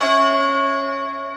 Key-bell_96.1.1.wav